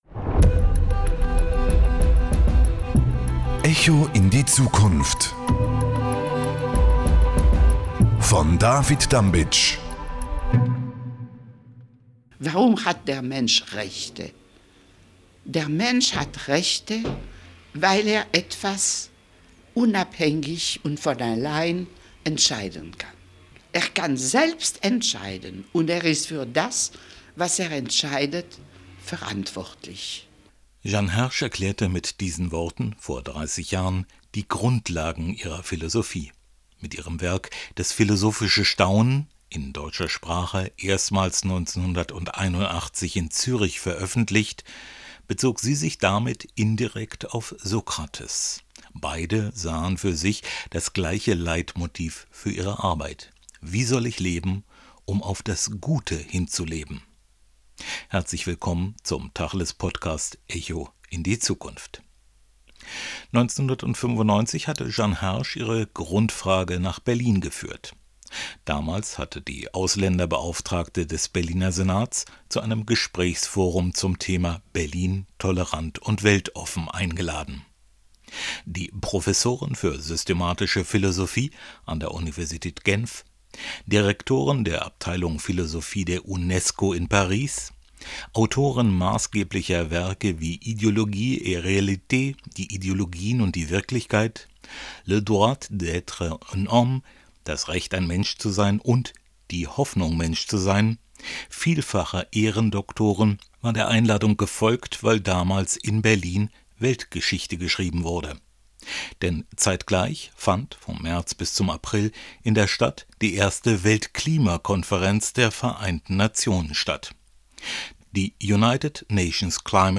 Im aktuellen Podcast kommt sie wieder zu Wort.